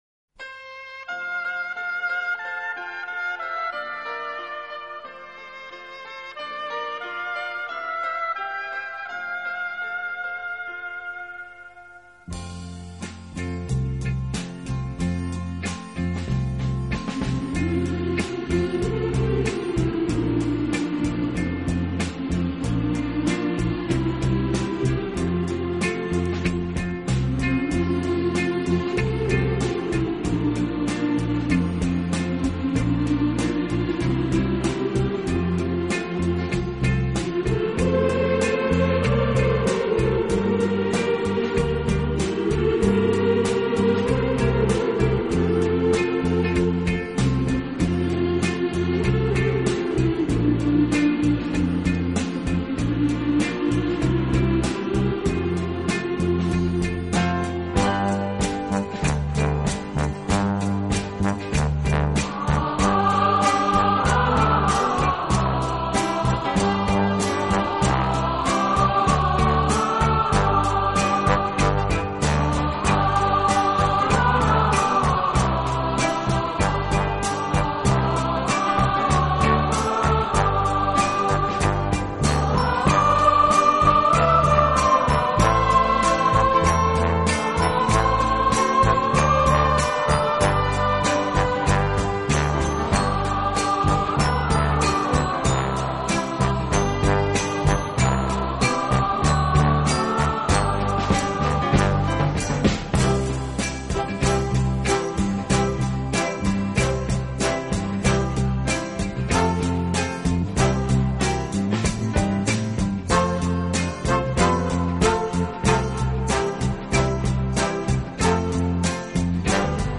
以热烈的旋律，独特的和声赢得千百万听众
此外，这个乐队还配置了一支训练有素，和声优美的伴唱合唱队。